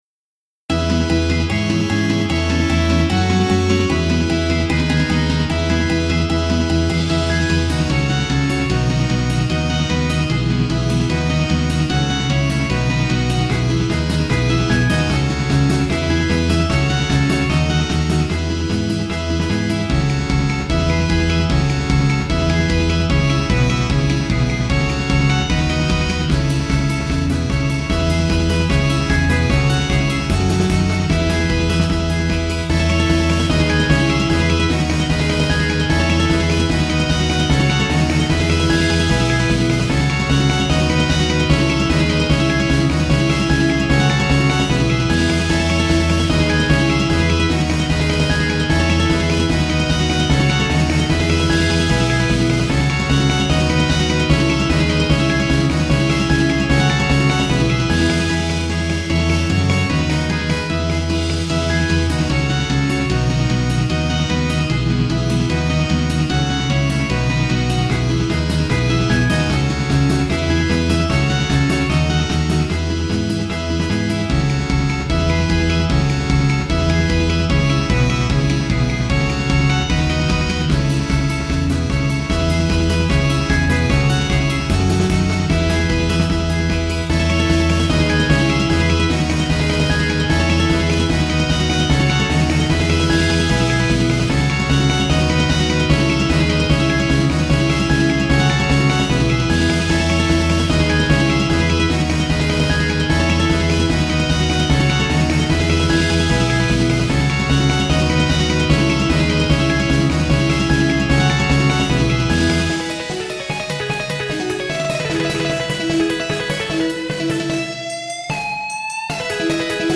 〜オフボーカル版〜